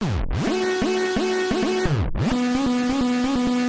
Tag: 130 bpm Electro Loops Synth Loops 637.76 KB wav Key : C